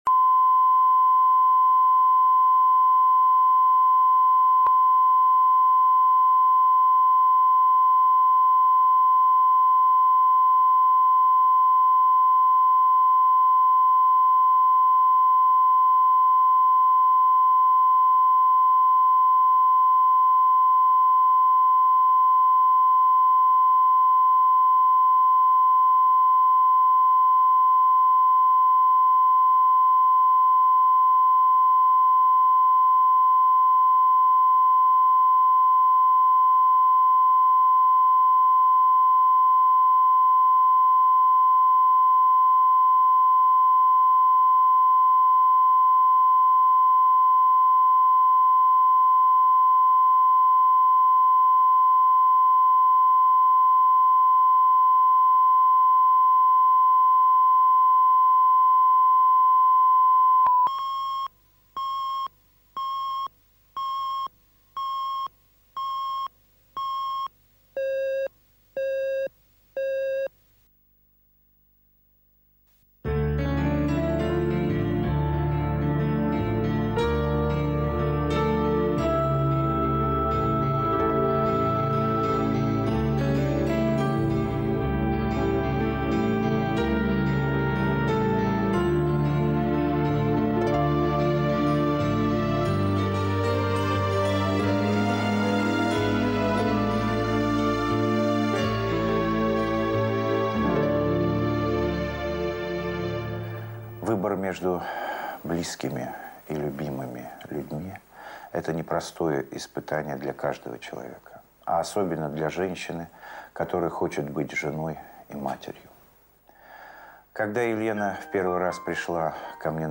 Аудиокнига Ради тебя | Библиотека аудиокниг